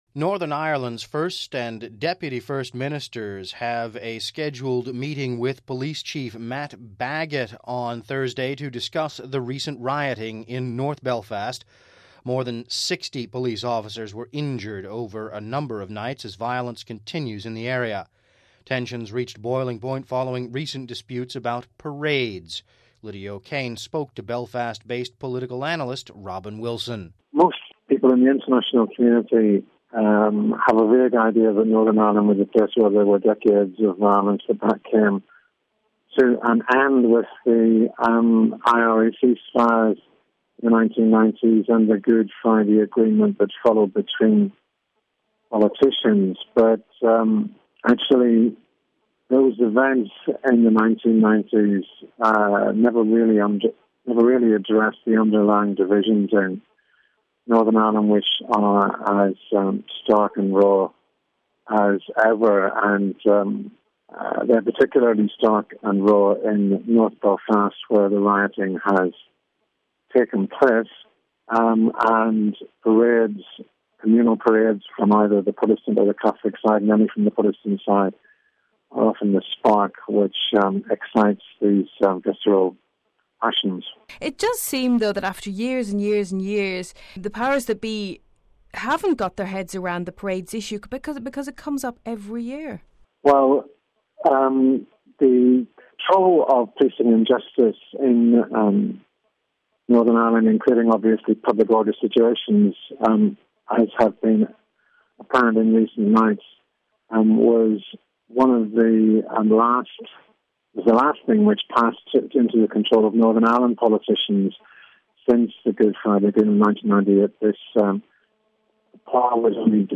(Vatican Radio) Northern Ireland's first and deputy first ministers are expected to meet Police Chief Matt Baggott today to discuss the recent rioting in north Belfast.